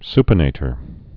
(spə-nātər)